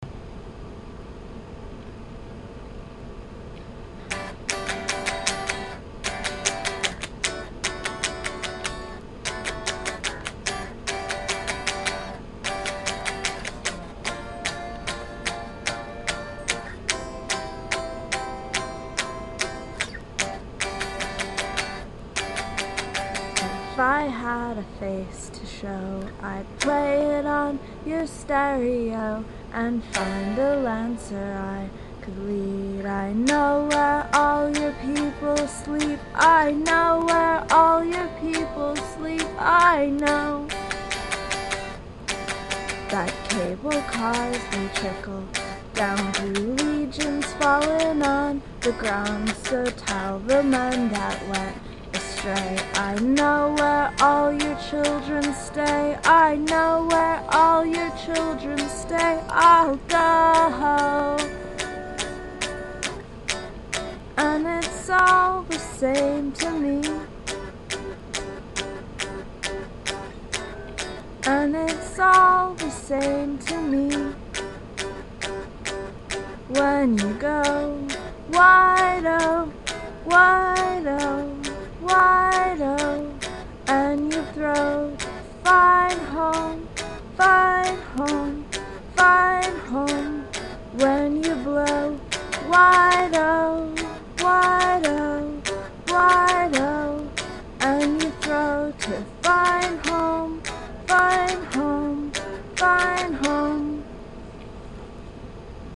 3. The melodies are lovely and catchy as a cold.